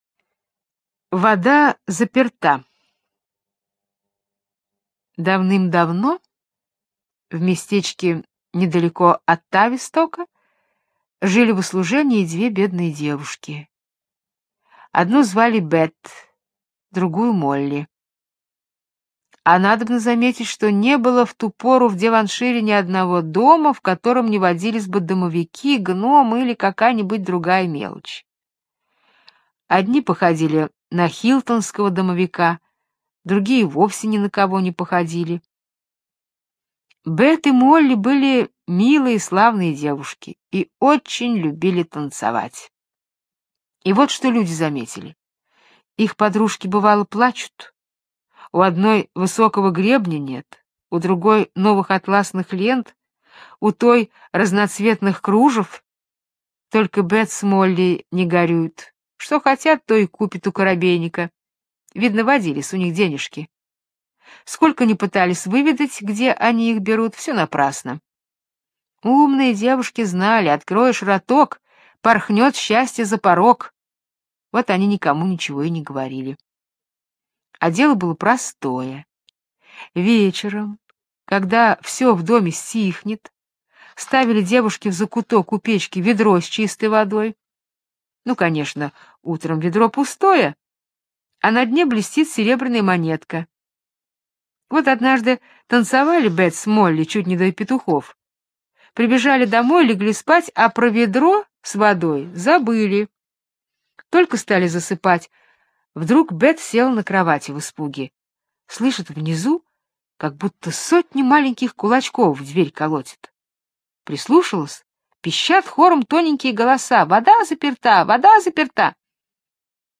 Вода заперта - британская аудиосказка - слушать онлайн